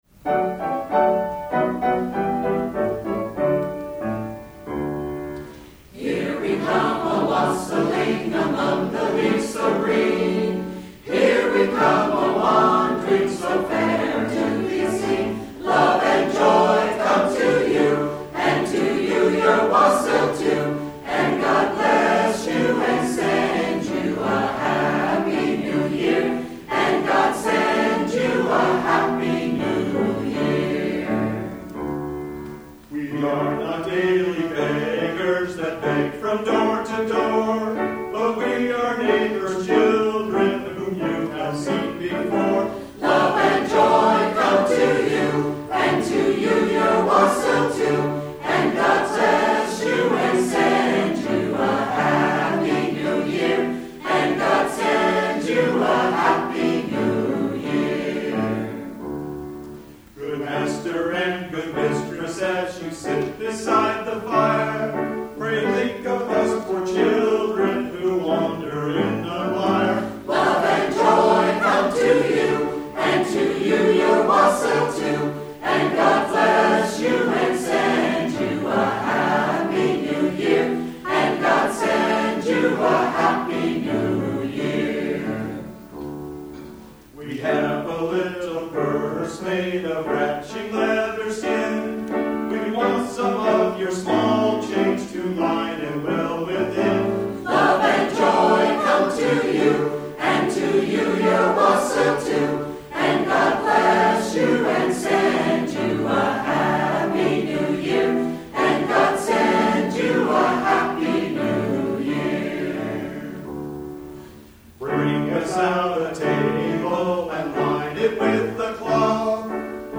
Center for Spiritual Living, Fremont, CA
2009 Winter Concert, Wednesday, December 16, 2009